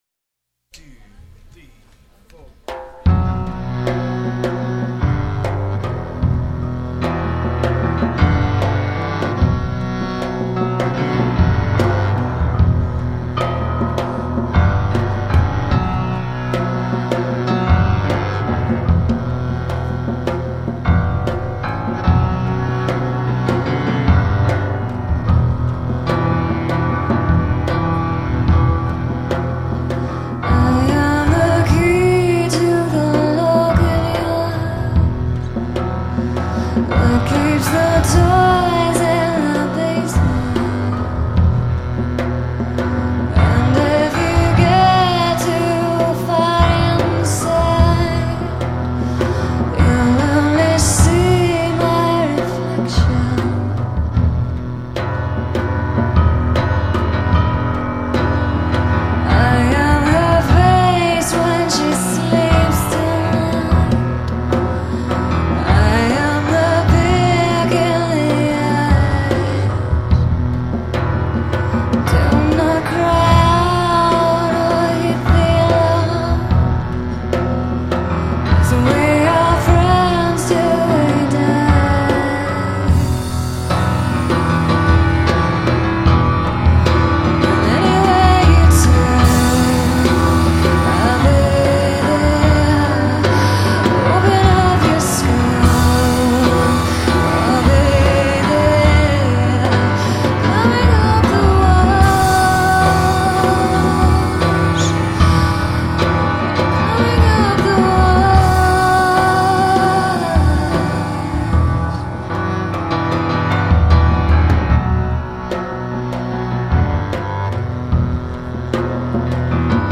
It’s so dark and thick, like walking through mud.
It’s really a Fiona piano style with a Sarah voice.